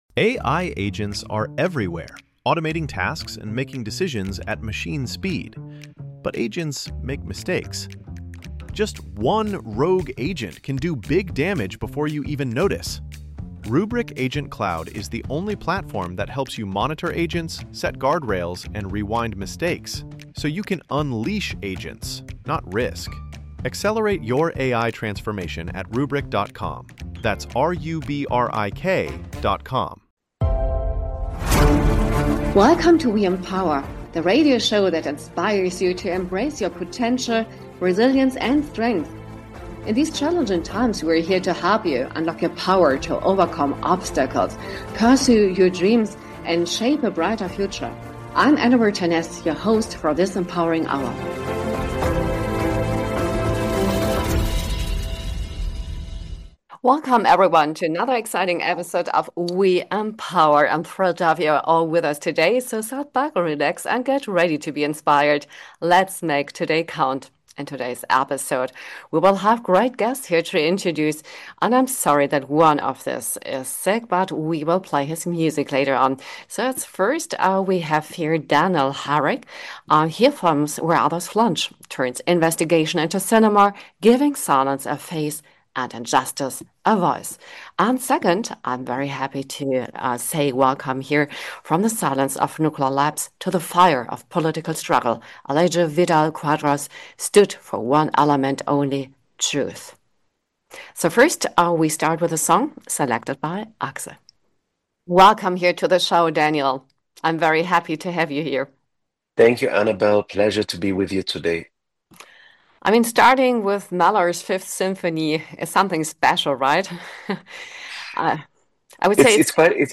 Welcome to "WE EMPOWER" – a radio show inspiring women to unleash their strengths and thrive in various life aspects. Featuring interviews with impressive female personalities across professions and discussions on women-led businesses, the show celebrates pioneers, especially female pioneers in history, science, art, and culture. It also explores self-development, mental health, and wellbeing, showcasing the most inspiriting books on these topics.